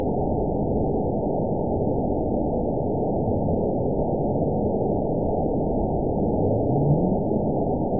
event 913719 date 04/18/22 time 15:24:38 GMT (3 years ago) score 9.55 location TSS-AB04 detected by nrw target species NRW annotations +NRW Spectrogram: Frequency (kHz) vs. Time (s) audio not available .wav